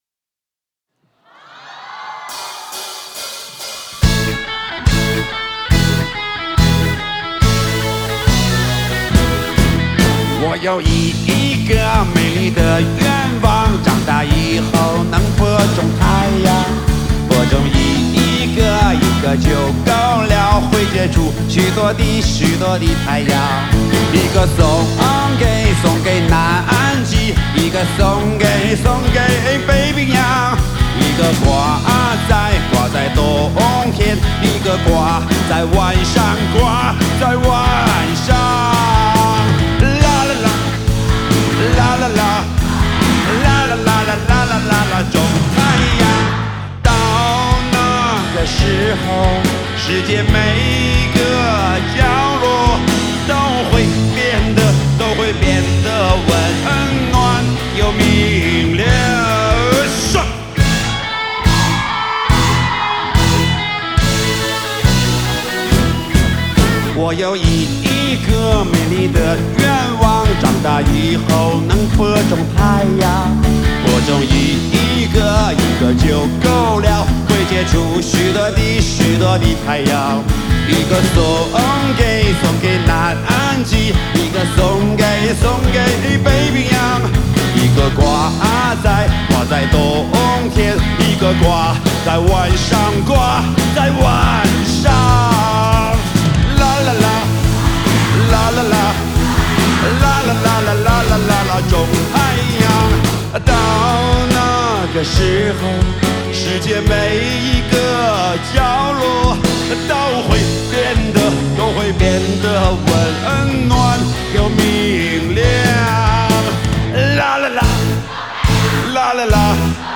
Ps：在线试听为压缩音质节选，体验无损音质请下载完整版
键盘
吉他
贝斯
鼓